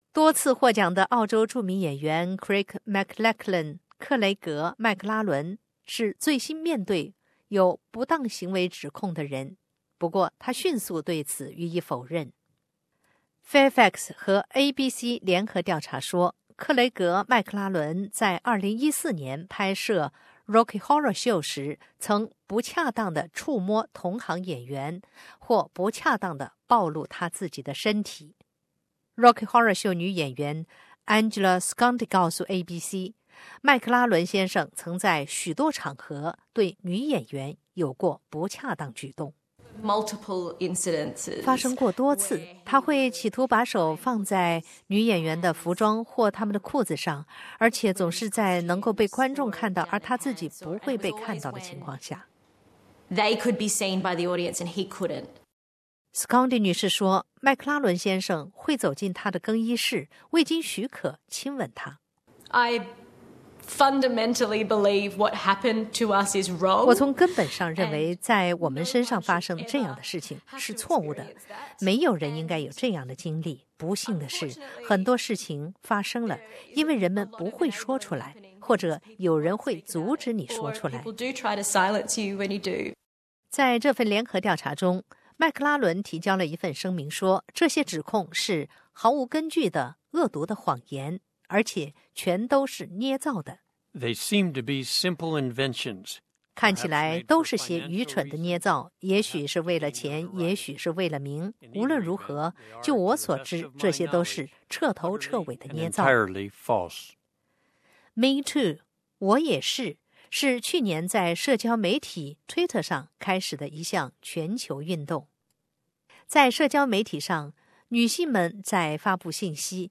Source: Getty SBS 普通话电台 View Podcast Series Follow and Subscribe Apple Podcasts YouTube Spotify Download (2.42MB) Download the SBS Audio app Available on iOS and Android 近日又有三名性骚扰受害者联合发声，曝光澳洲娱乐行业的性骚扰。